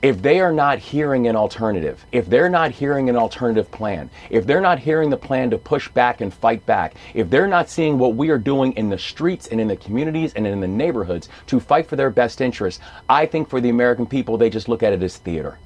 Governor Wes Moore was a guest on this week’s Face The Nation program and he spoke openly about taking steps to defend Maryland against the so-called attacks of the Trump Administration, including the decision to not move the FBI to Greenbelt.  The governor said that the public is becoming confused by the mixed signals sent by federal and state officials…